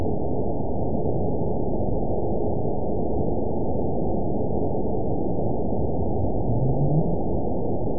event 920964 date 04/20/24 time 21:51:24 GMT (1 year ago) score 9.58 location TSS-AB03 detected by nrw target species NRW annotations +NRW Spectrogram: Frequency (kHz) vs. Time (s) audio not available .wav